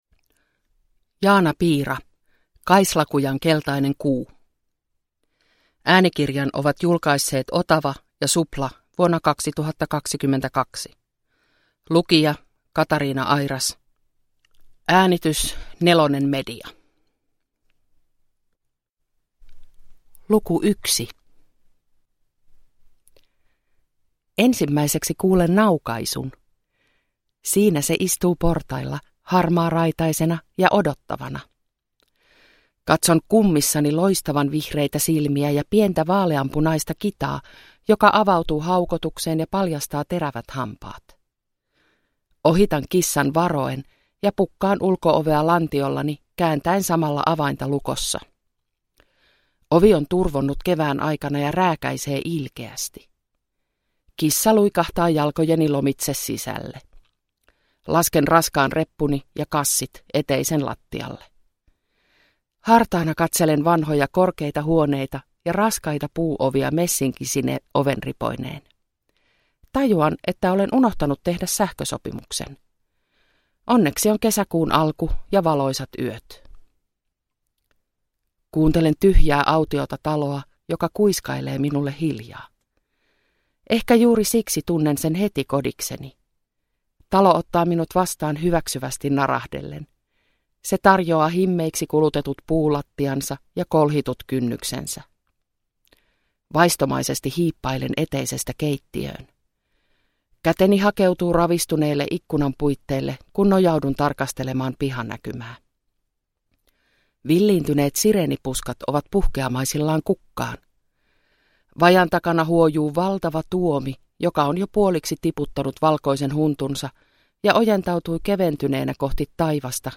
Kaislakujan keltainen kuu – Ljudbok – Laddas ner